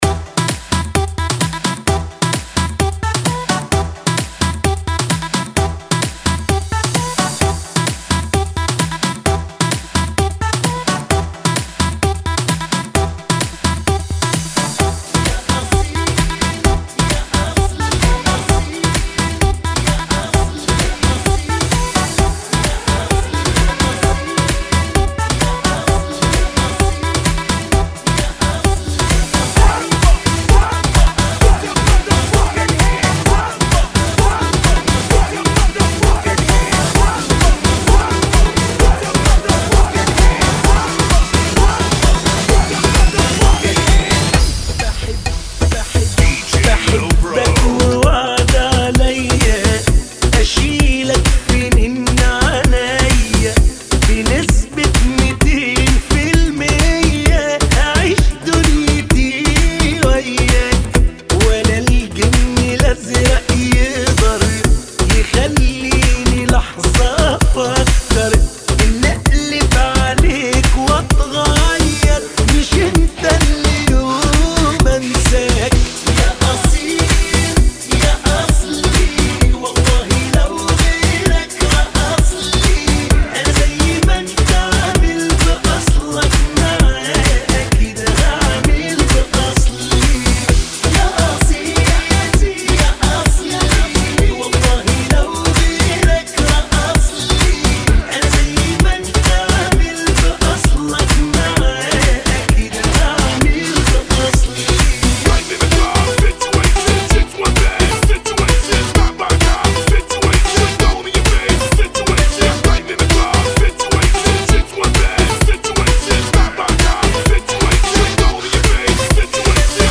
[ 130 bpm ]